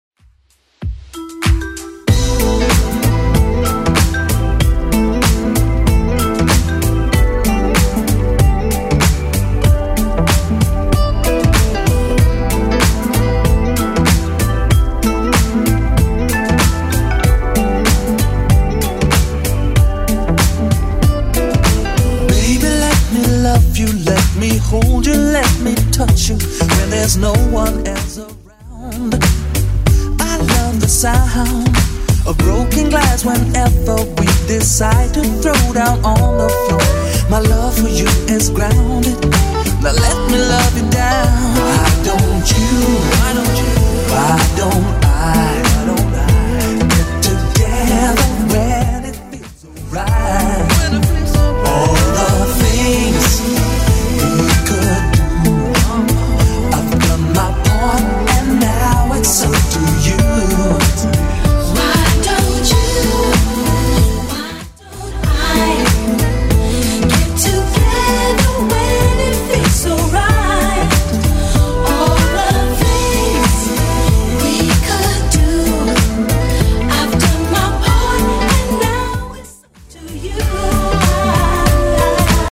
Genre: 80's
BPM: 160